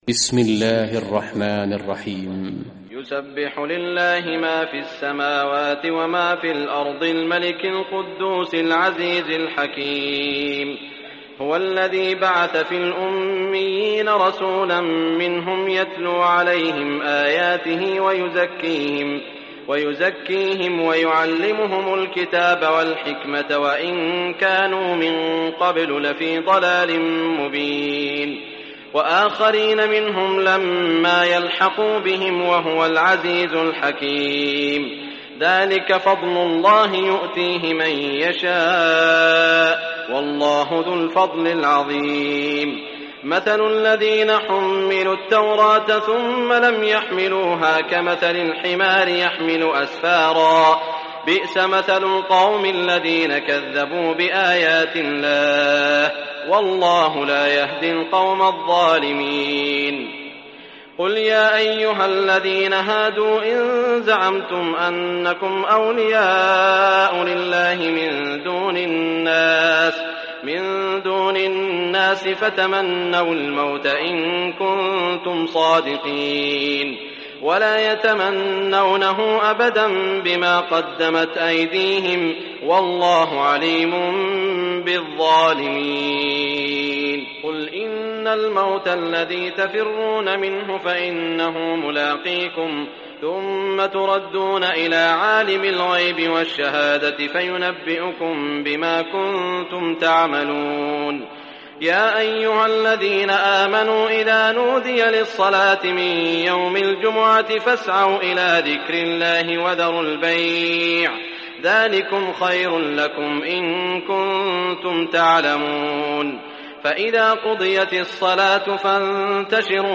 Surah Jummah Recitation by Sheikh Saud al Shuraim
Surah Jummah, listen or play online mp3 tilawat / recitation in Arabic in the beautiful voice of Sheikh Saud al Shuraim.